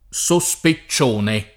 SoSpe©©1ne], sospezione [